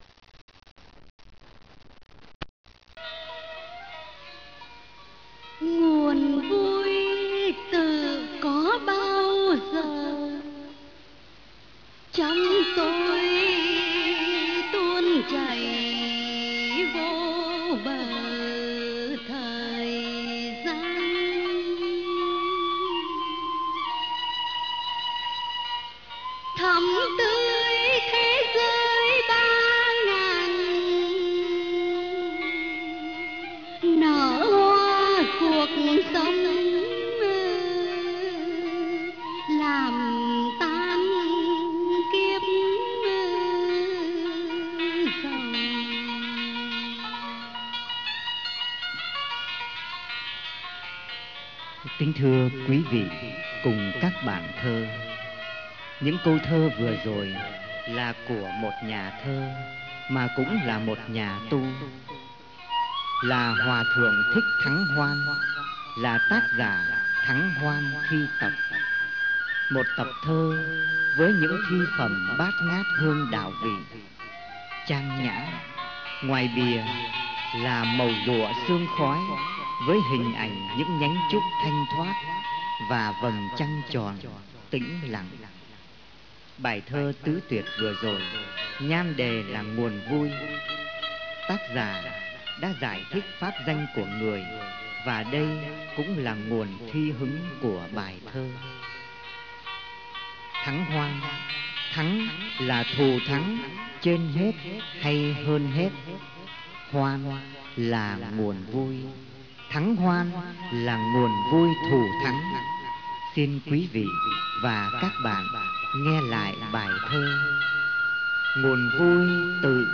PDF AUDIO Trang Đọc và Ngâm (Tái bản lần thứ hai 1999) Ý NGHĨA BÌA THƠ Vơ minh vũ trụ Phủ kín đường về.